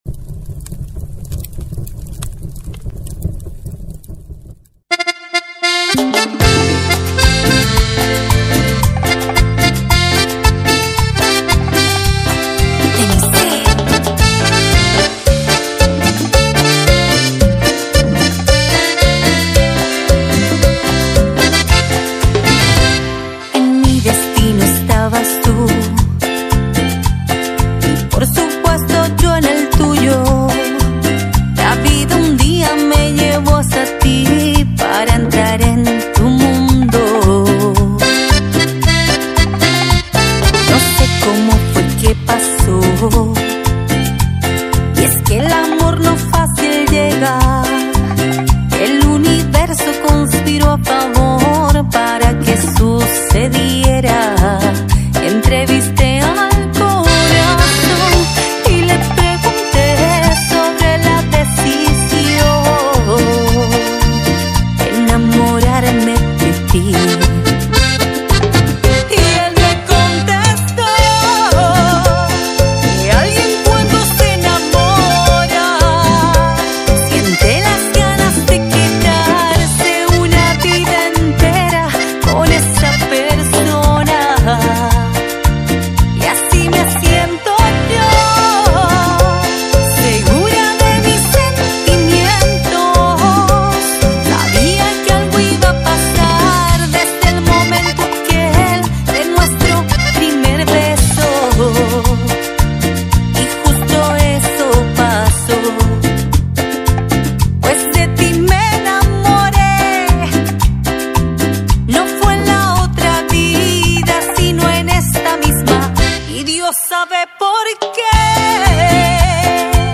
Carpeta: Cumbia y + mp3